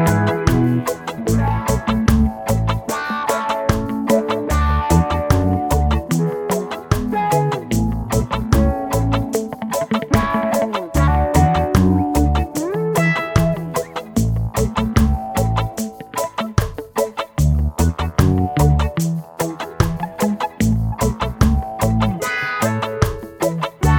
Minus Wah Guitar And Solo Reggae 3:56 Buy £1.50